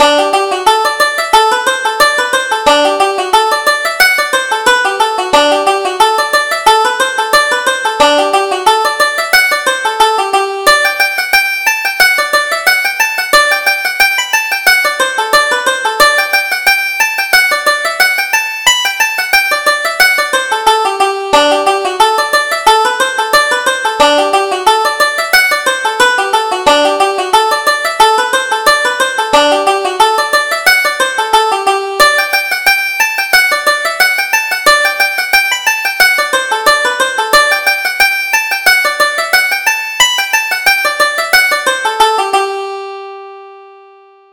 Reel: My Sweetheart Jane